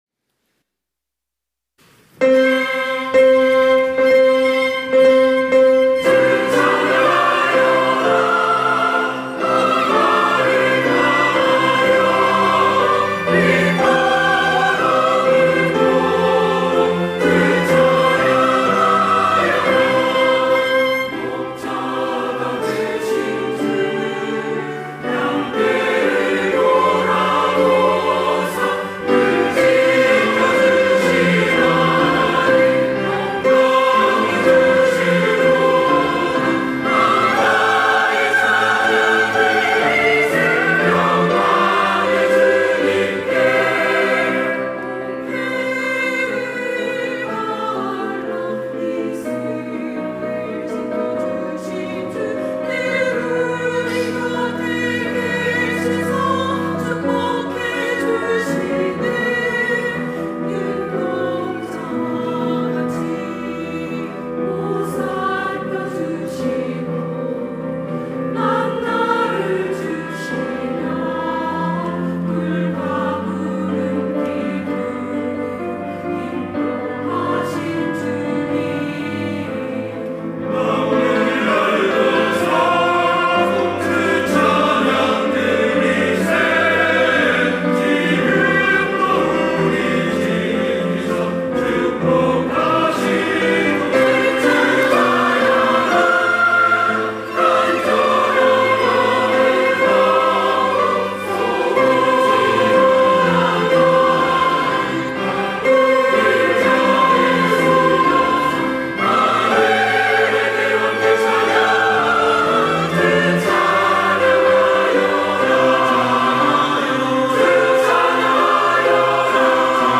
호산나(주일3부) - 마음 다해 찬양
찬양대